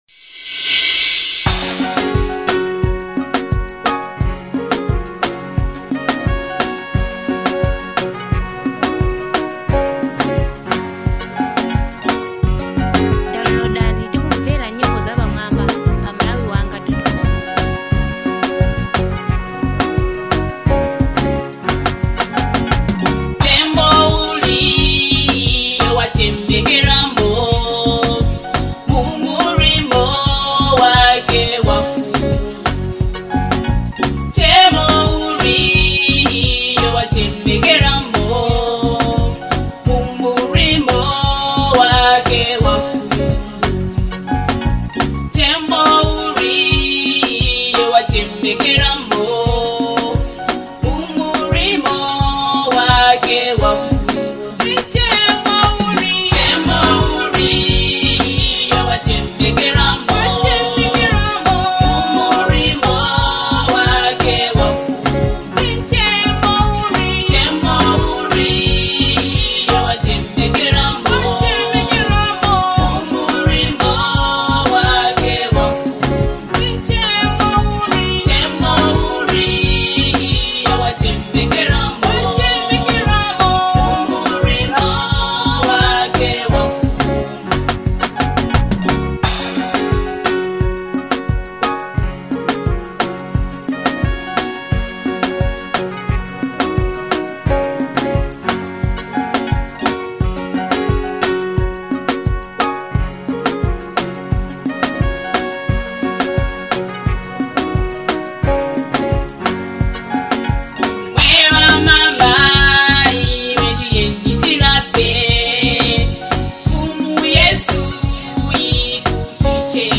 Worship